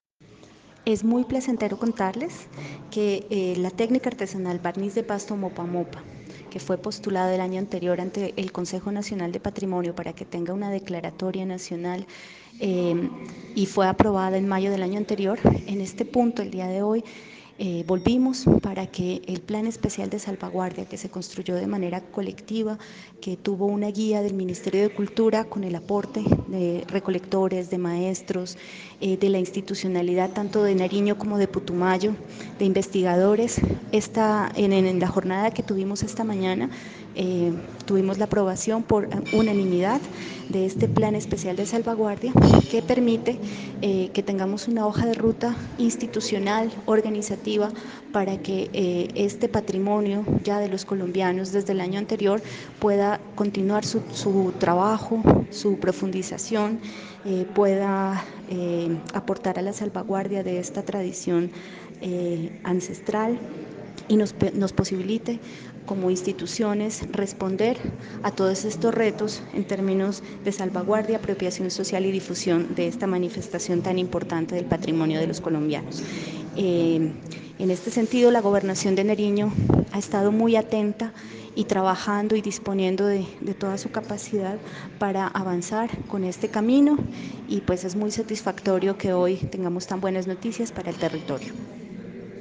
Entrevista a Gloria Garzón - Directora Administrativa de Cultura de Nariño: Su navegador no soporta AUDIO.